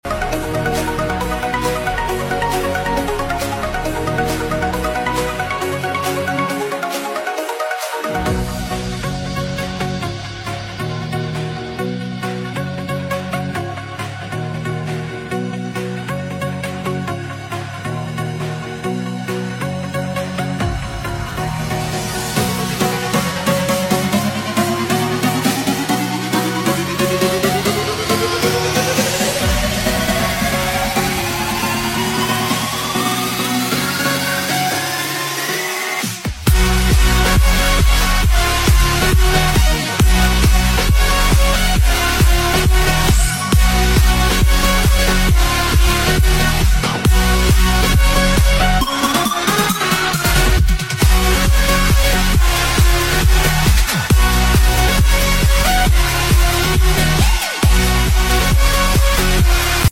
Nhạc EDM